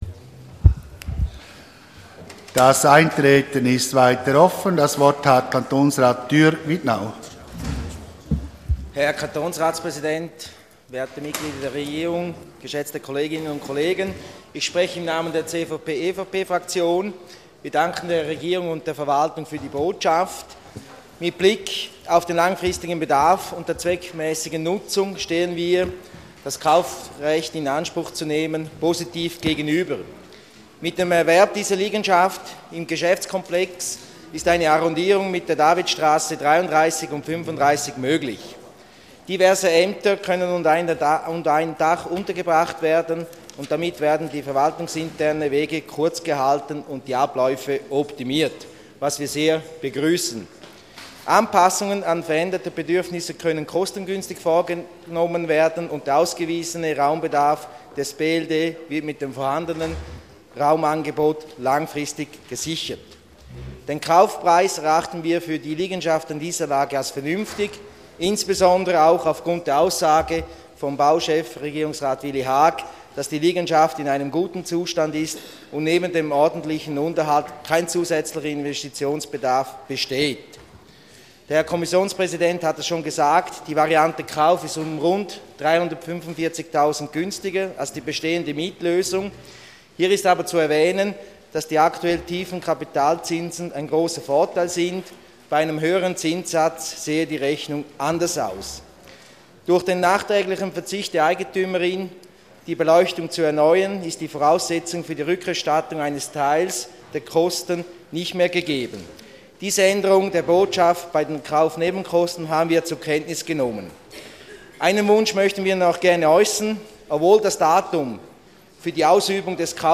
Session des Kantonsrates vom 2. bis 4. Juni 2014